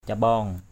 /ca-ɓɔ:ŋ/ (d.) miệng = ouverture. cambaong jaong c_O” _j” miệng rìu = gorge de la hache; cambaong jraow c_O” _j<| hà khẩu; miệng cái đó = embouchure de la nasse....
cambaong.mp3